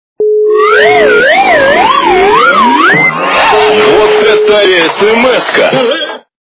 » Звуки » звуки для СМС » Звонок для СМС - Вот это СМС-ка
При прослушивании Звонок для СМС - Вот это СМС-ка качество понижено и присутствуют гудки.